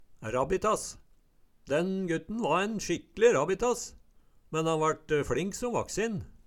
Høyr på uttala Ordklasse: Substantiv hankjønn Kategori: Karakteristikk Attende til søk